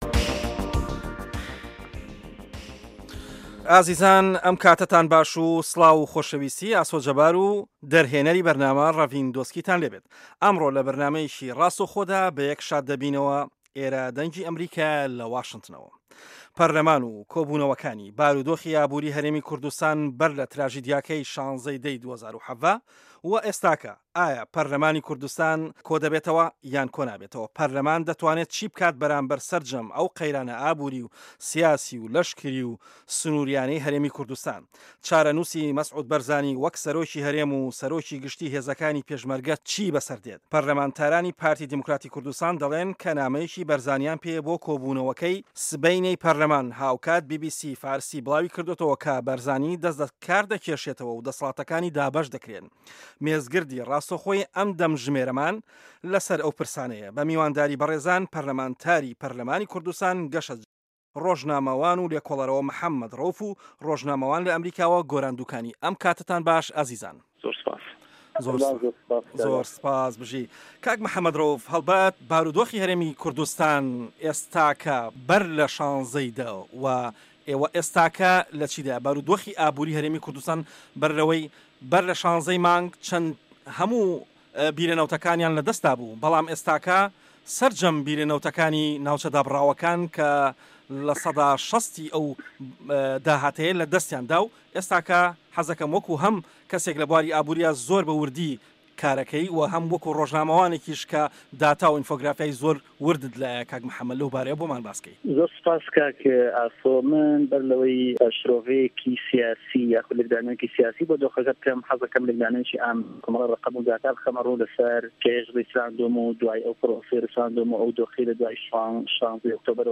دەقی مێزگردەکە